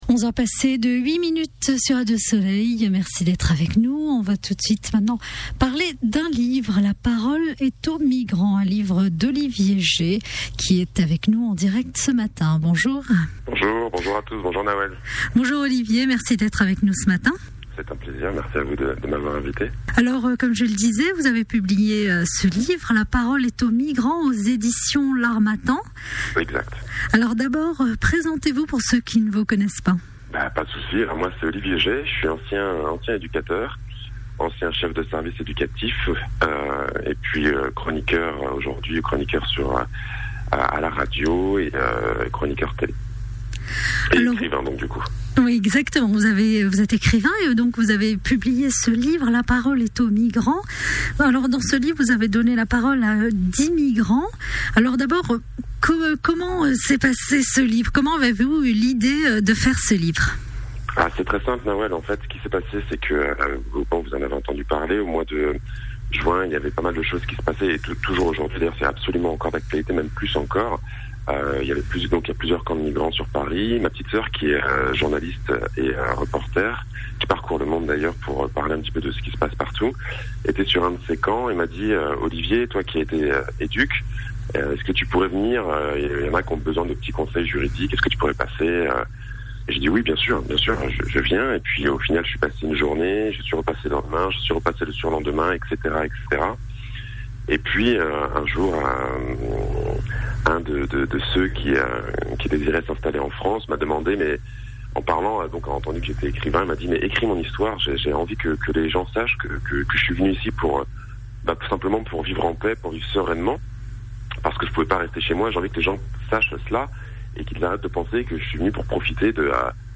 ITV radio soleil.mp3